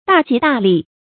大吉大利 注音： ㄉㄚˋ ㄐㄧˊ ㄉㄚˋ ㄌㄧˋ 讀音讀法： 意思解釋： 非常吉祥、順利。